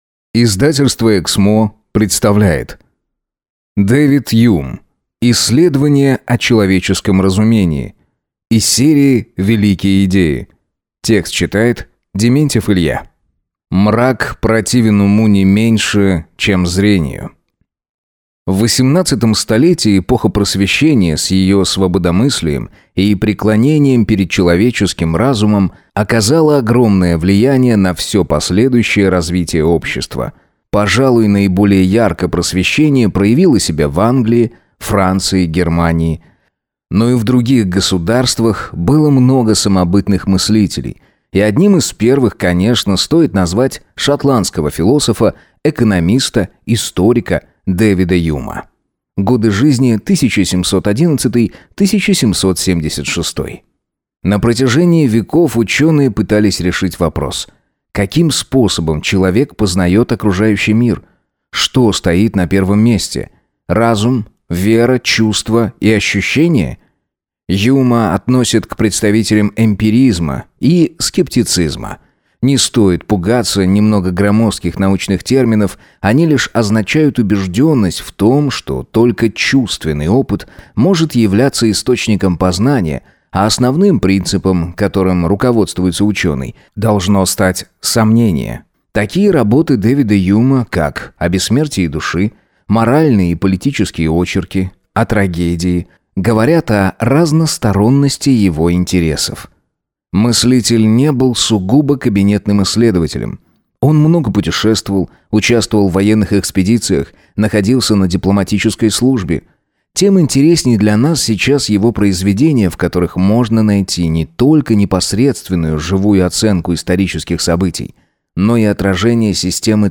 Аудиокнига Исследование о человеческом разумении | Библиотека аудиокниг